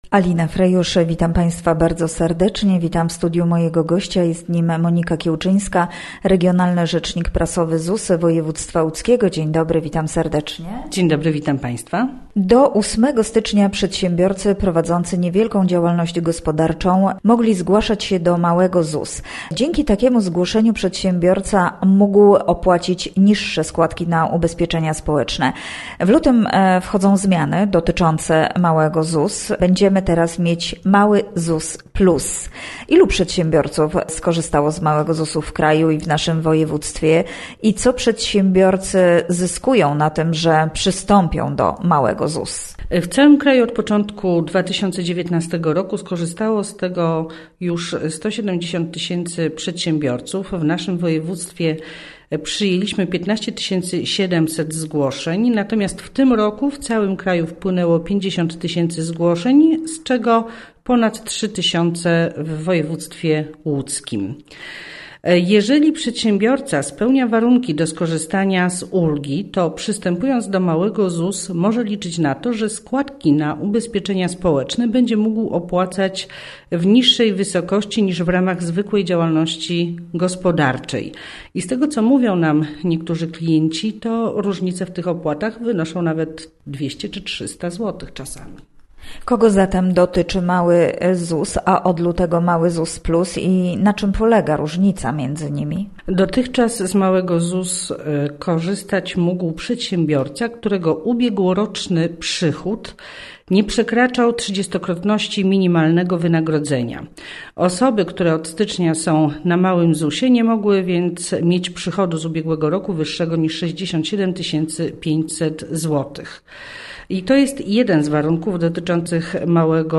w rozmowie